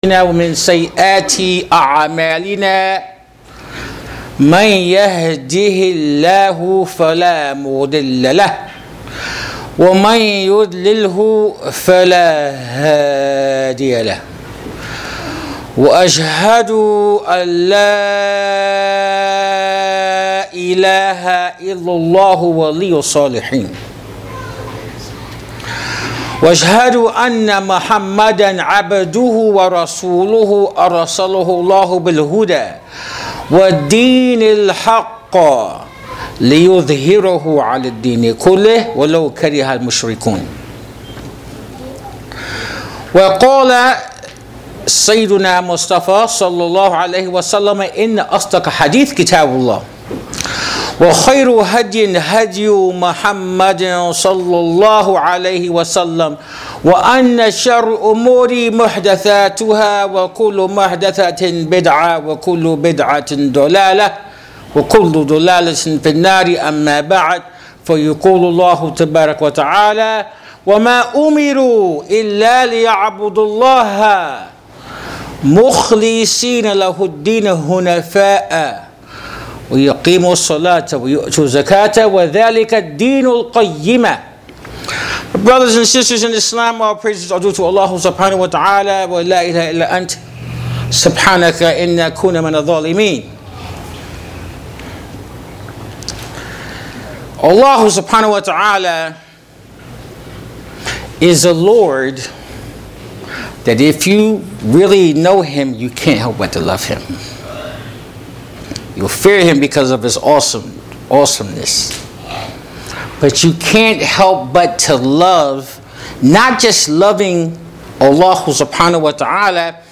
Audio Khutba